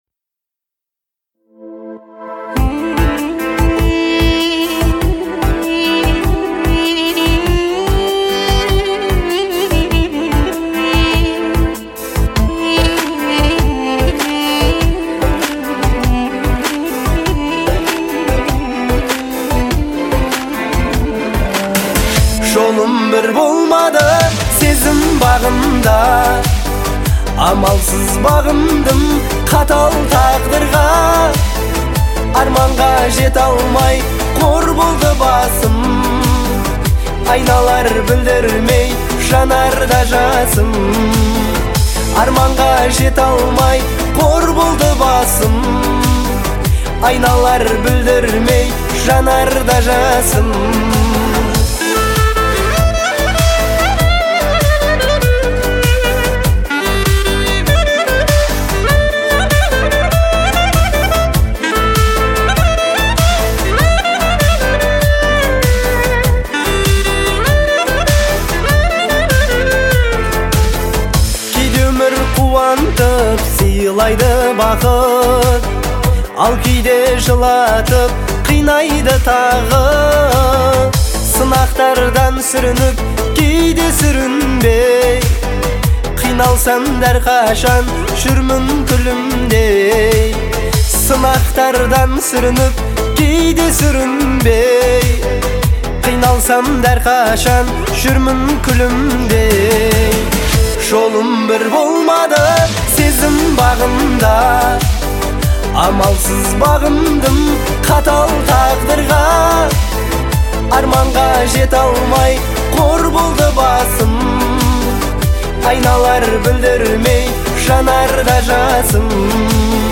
это трогательная песня в жанре казахского поп-фолка
обладая мощным и выразительным голосом